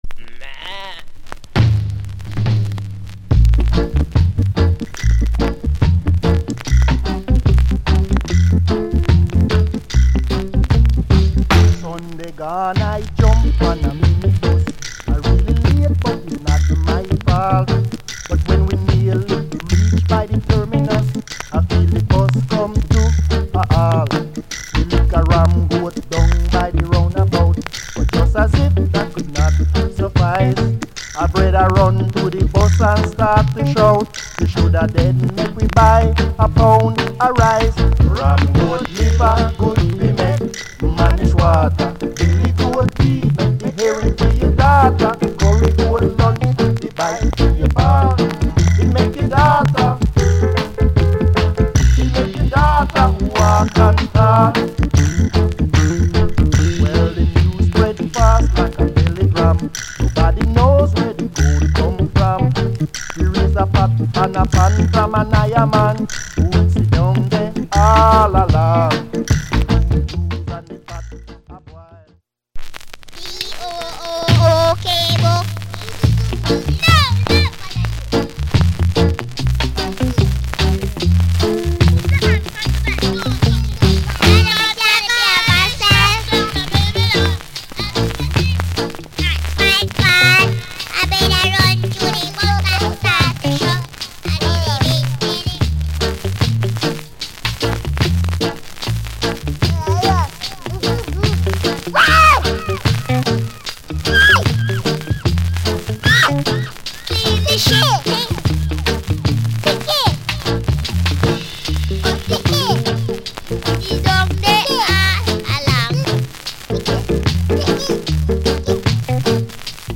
Genre Reggae70sMid / Male Vocal